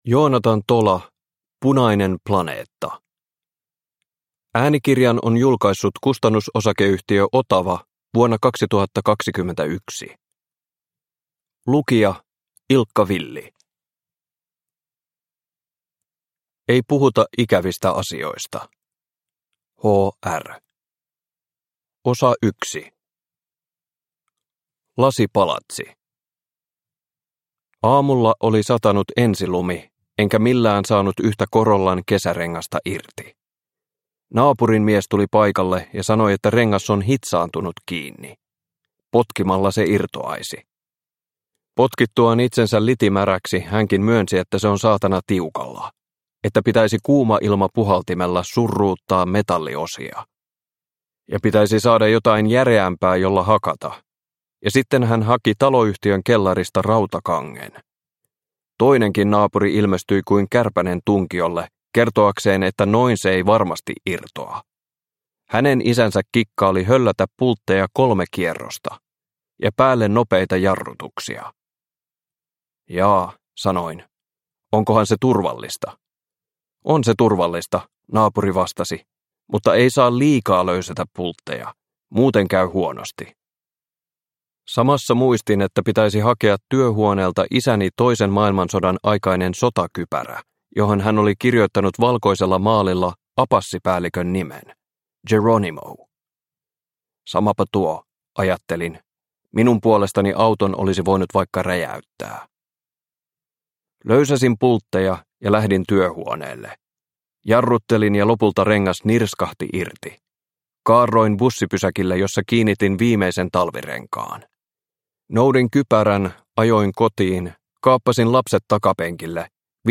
Punainen planeetta – Ljudbok – Laddas ner
Uppläsare: Ilkka Villi